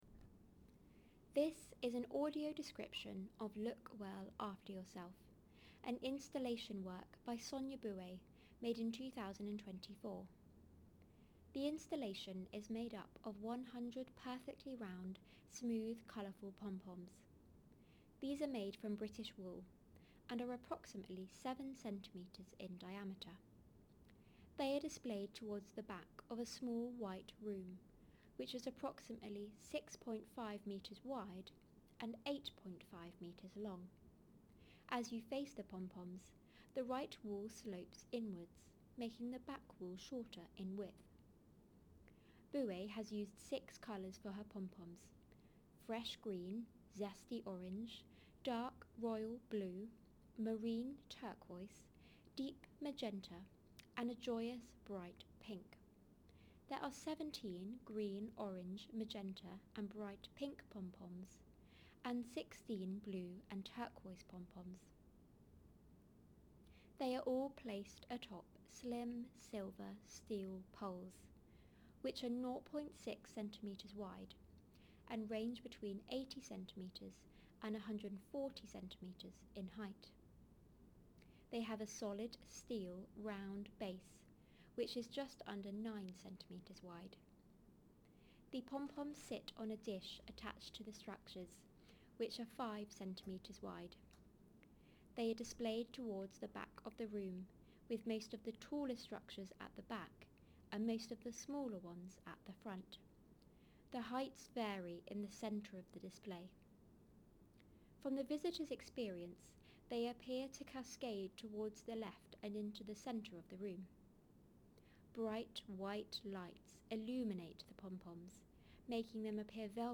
Audio Description of Sonia Boué’s Look Well After Yourself